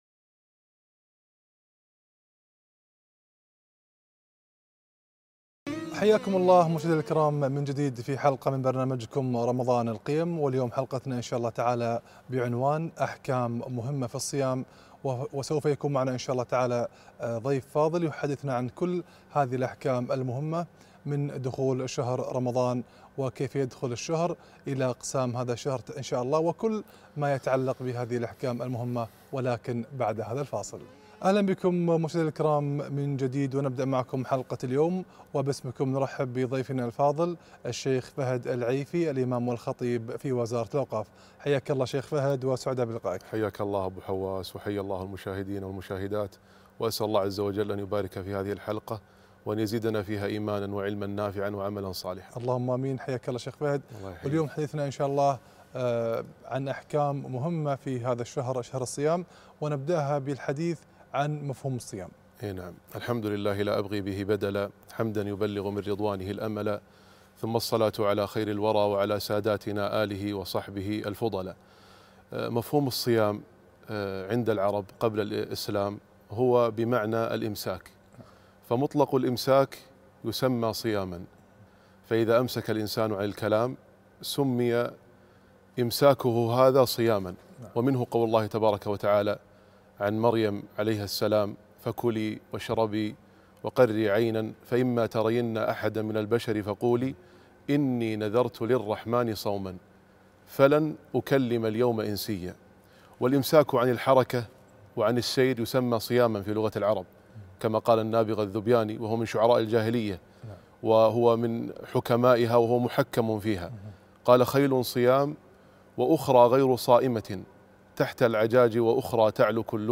أحكام مهمة في الصيام - لقاء إذاعي برنامج رمضان القيم